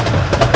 TRAIN SC.wav